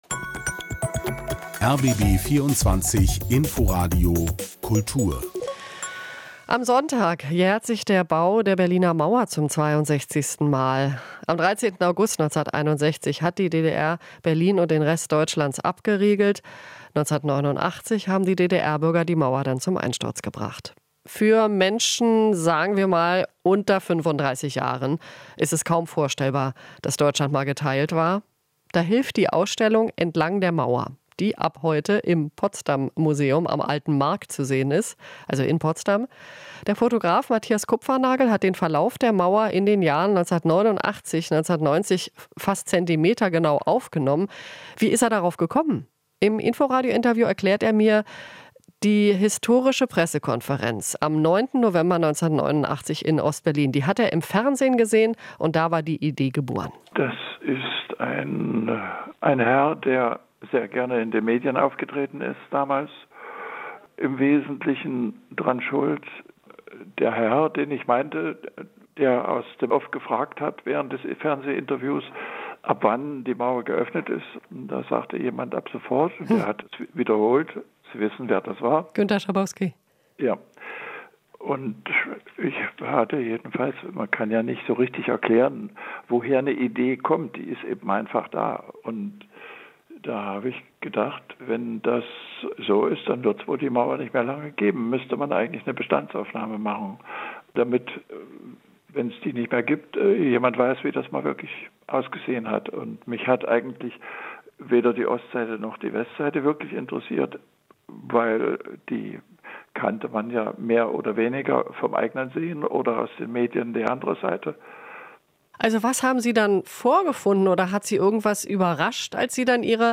Interview - "Entlang der Mauer": Fotoausstellung im Potsdam Museum